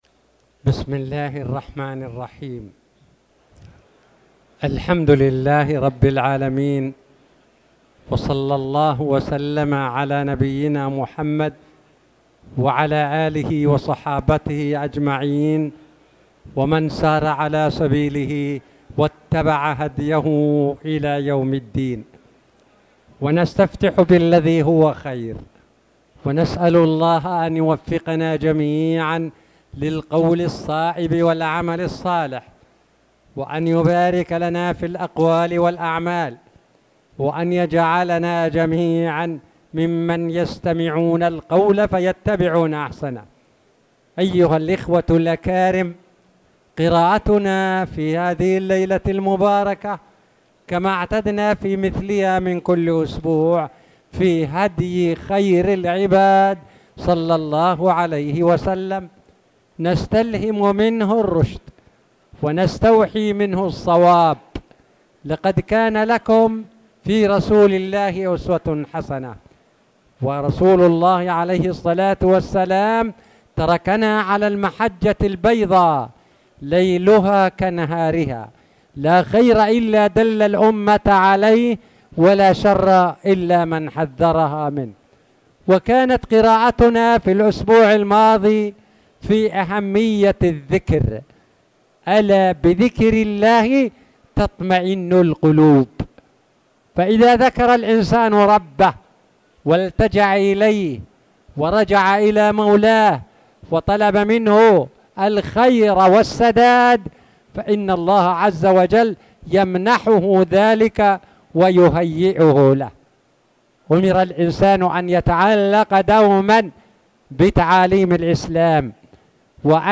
تاريخ النشر ٢٣ رجب ١٤٣٨ هـ المكان: المسجد الحرام الشيخ